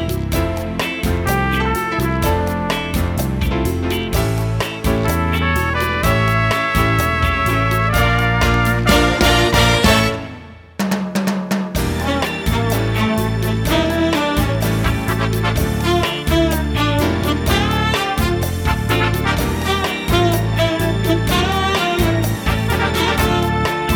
Two Semitones Down Jazz / Swing 3:07 Buy £1.50